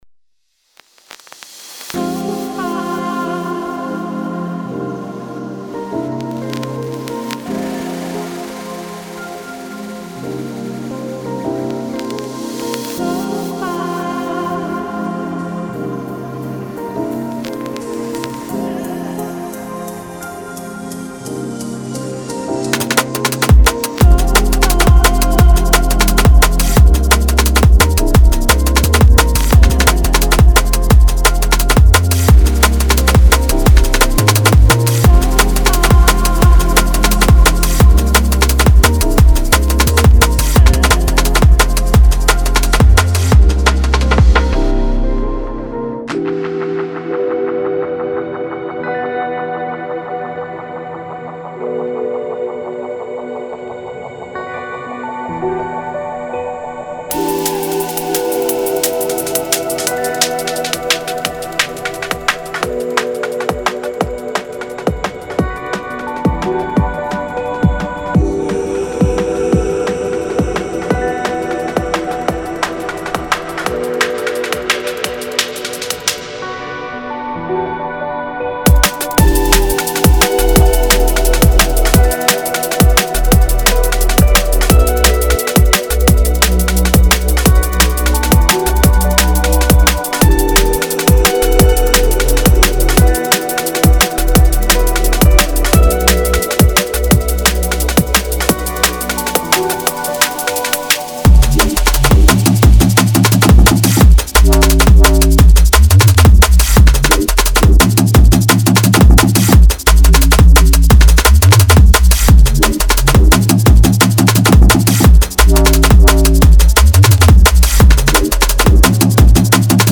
デモサウンドはコチラ↓
Genre:Drum and Bass